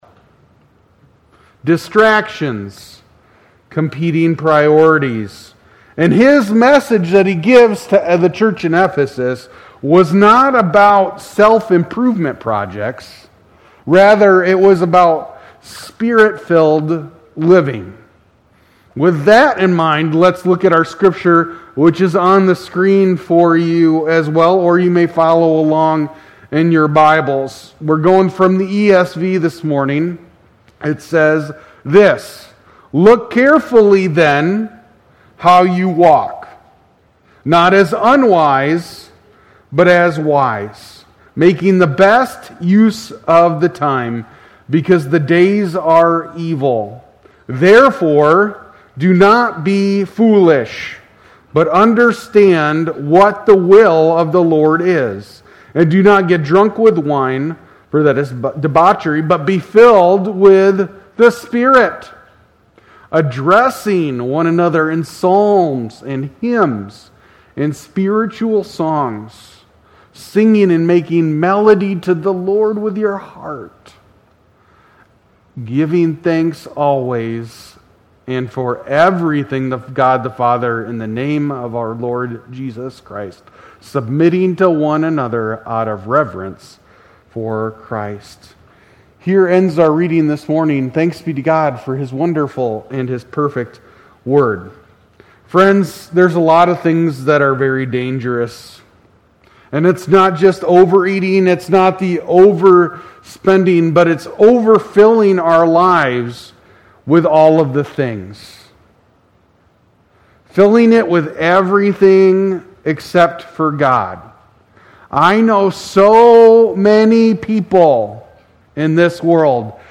2025 at Cornerstone Church in Pella.